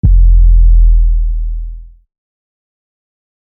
TS - 808 (8).wav